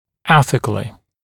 [‘eθɪklɪ] [‘эсикли] этично; с этической точки зрения